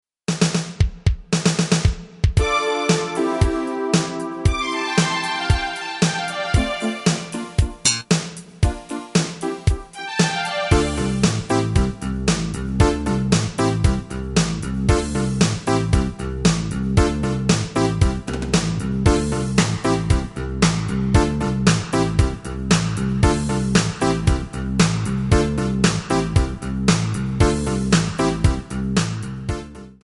Backing track Karaoke
Pop, 1980s